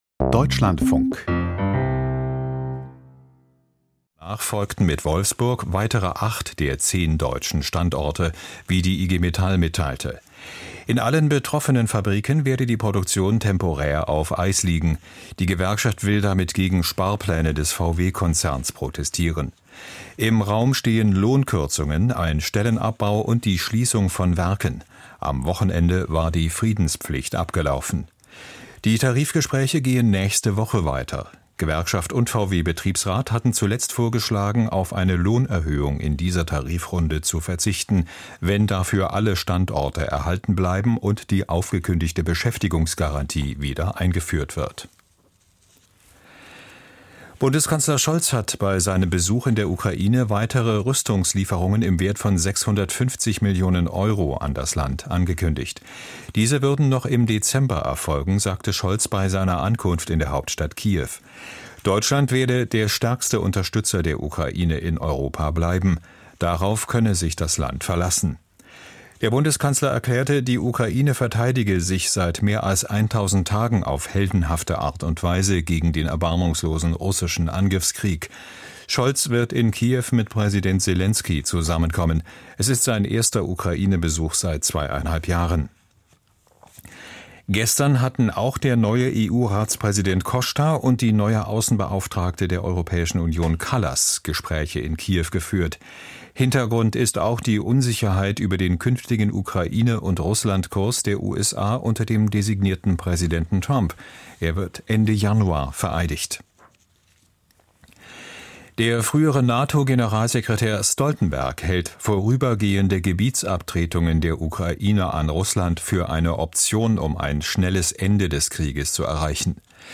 Die Deutschlandfunk-Nachrichten vom 02.12.2024, 09:30 Uhr